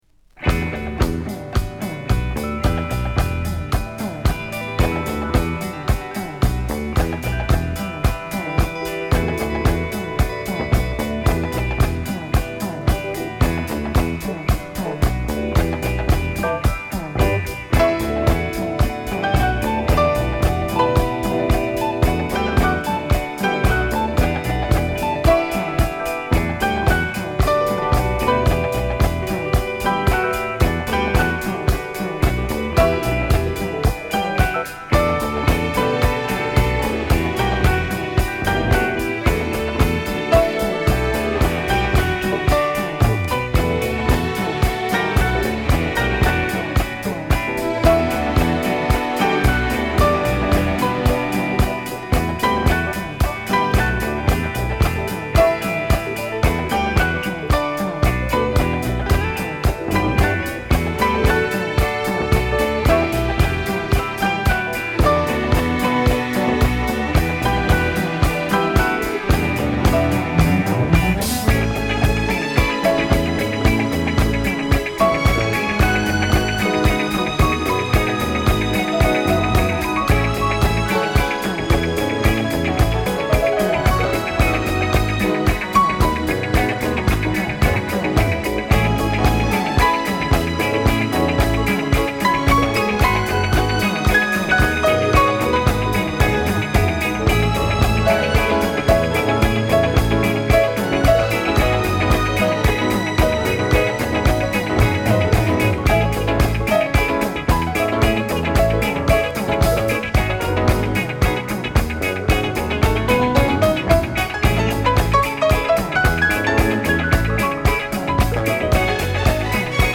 ジワジワとハメる独特のダンストラックは外しません！